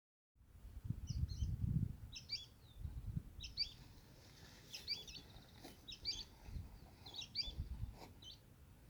Sooty-fronted Spinetail (Synallaxis frontalis)
Life Stage: Adult
Location or protected area: Reserva Natural Urbana La Malvina
Condition: Wild
Certainty: Recorded vocal
Pijui_frente_gris.mp3